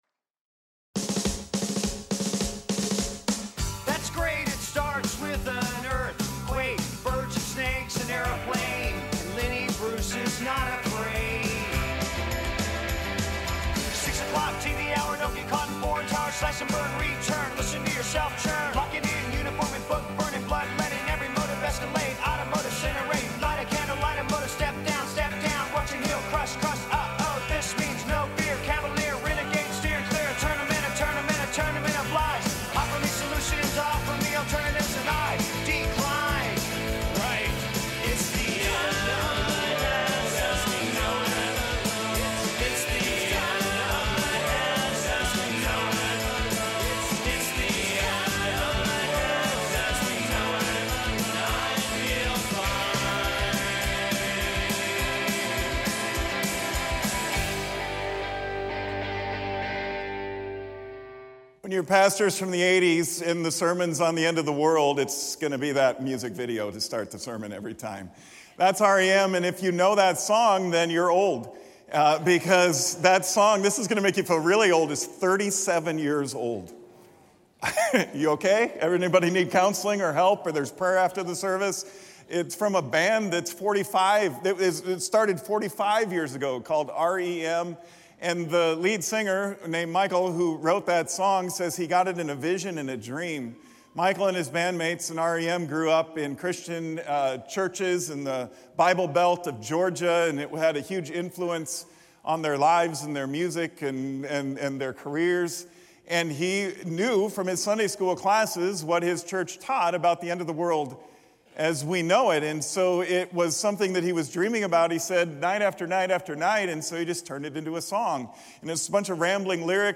at Lutheran Church of Hope in West Des Moines, Iowa.